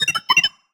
beeps2.ogg